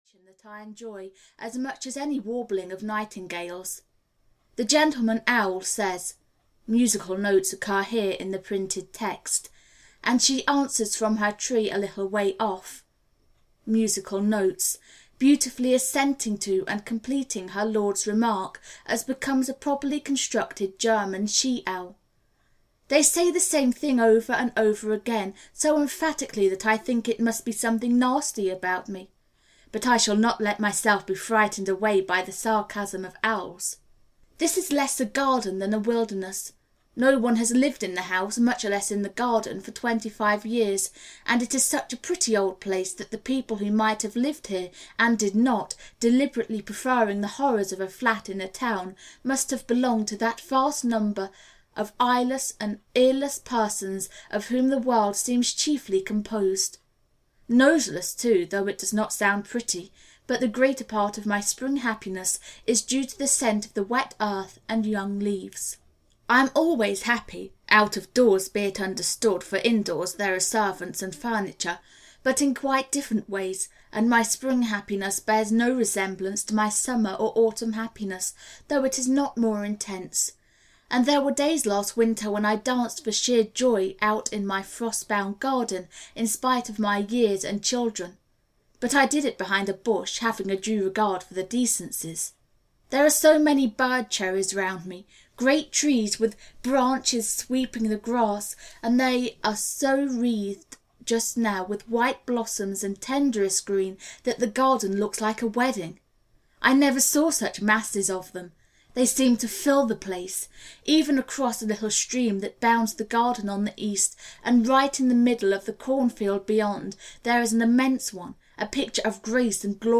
Audio knihaElizabeth and her German Garden (EN)
Ukázka z knihy